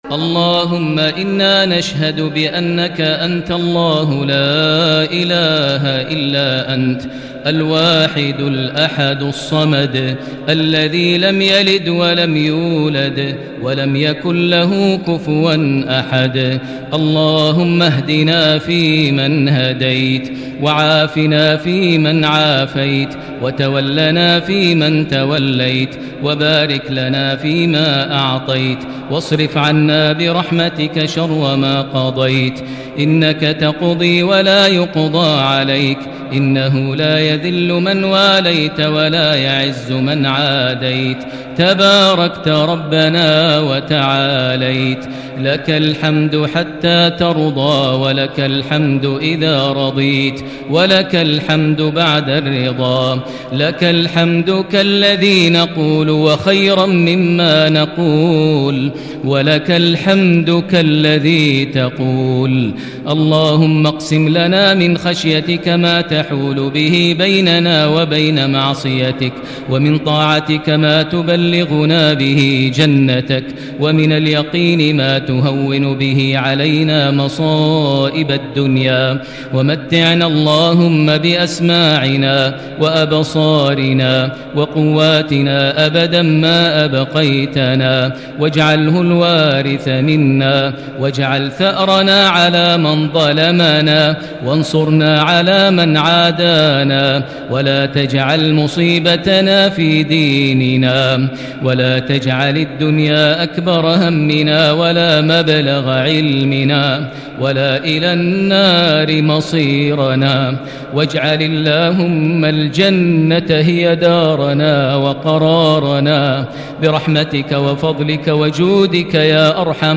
دعاء القنوت ليلة 28 رمضان 1441هـ > تراويح الحرم المكي عام 1441 🕋 > التراويح - تلاوات الحرمين